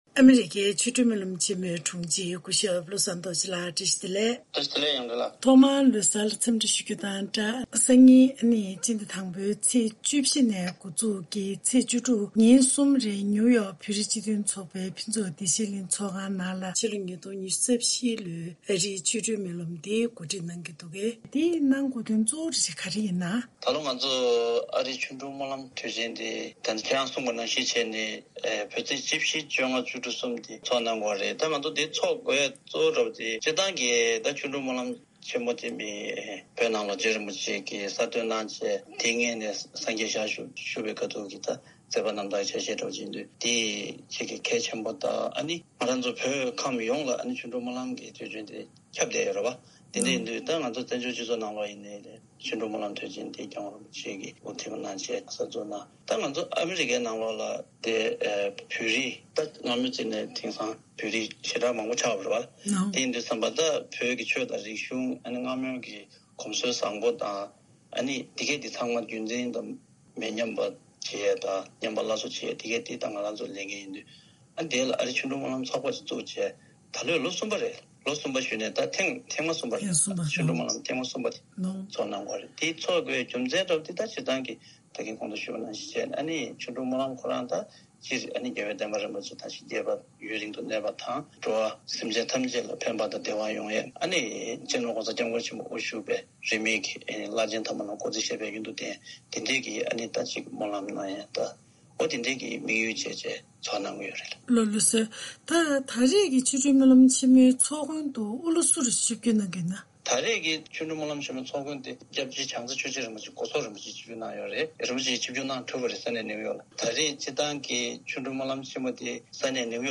དམིགས་བསལ་གནས་འདྲིའི་ལེ་ཚན་ནང།